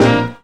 JAZZ STAB 22.wav